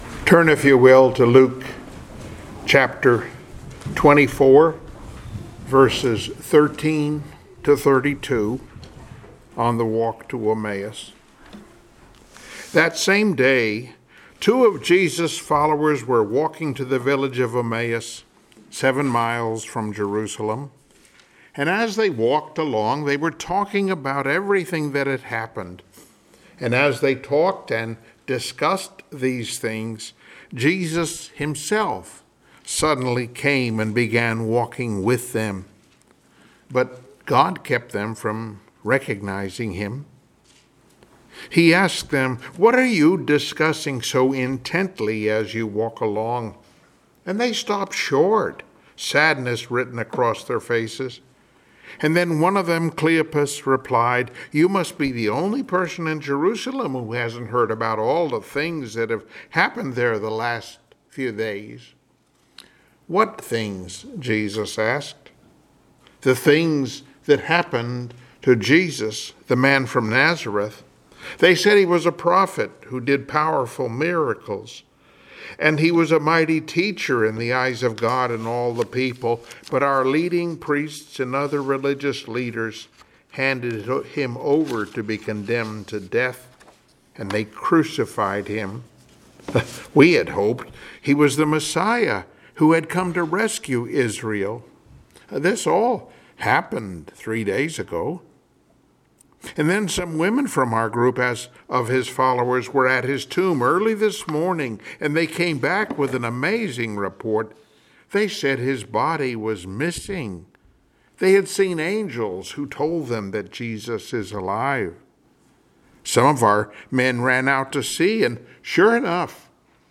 Passage: Jeremiah 29:11 Service Type: Sunday Morning Worship